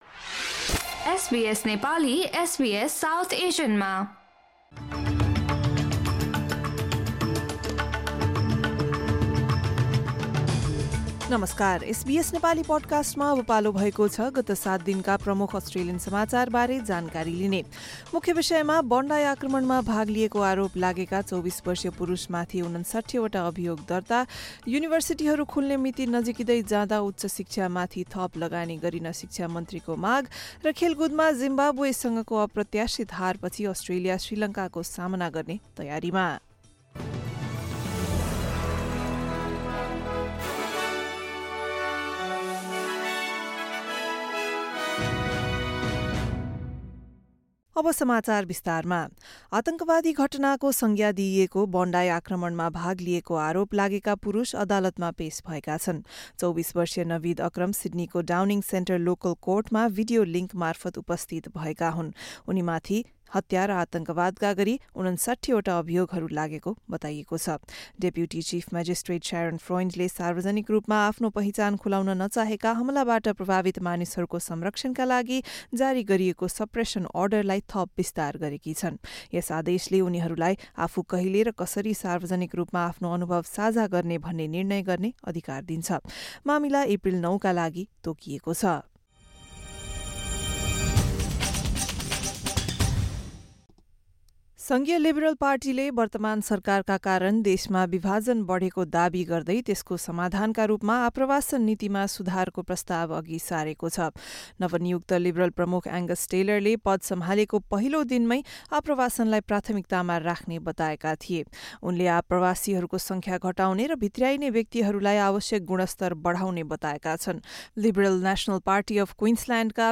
बोन्डाइ आक्रमणमा भाग लिएको आरोप लागेका २४ वर्षीय पुरुष माथि ५९ अभियोग दर्ता, युनिभर्सिटीहरू खुल्ने मिति नजिकिँदै जाँदा उच्च शिक्षा माथि थप लगानी गरिन शिक्षा मन्त्रीको माग र खेलकुदमा, जिम्बाब्वेसँगको अप्रत्याशित हार पछि पुरुष तर्फको टी२० क्रिकेट विश्वकपमा अस्ट्रेलिया श्रीलङ्काको सामना गर्ने तयारीमा लगायत एक हप्ता यताका प्रमुख घटनाहरूबारे एसबीएस नेपालीबाट समाचार सुन्नुहोस्।